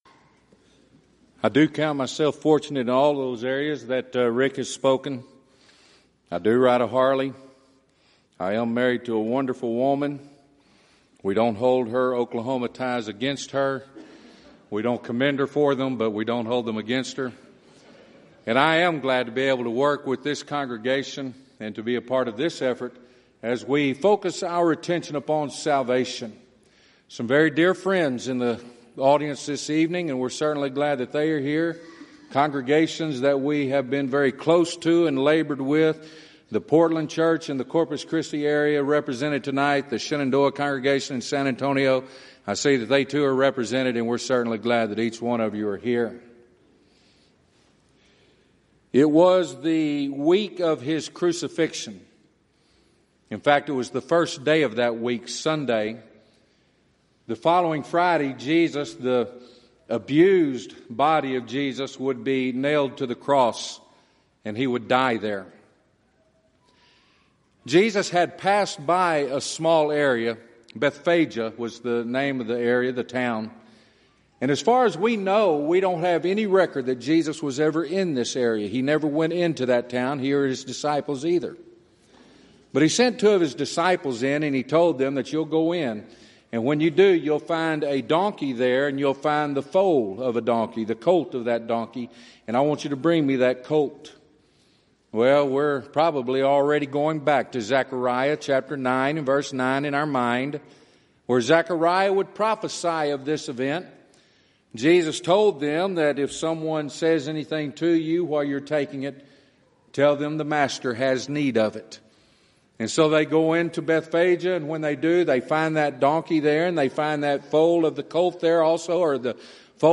Event: 29th Annual Southwest Lectures Theme/Title: Proclaiming Christ: Called Unto Salvation
lecture